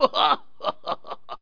laugh08.mp3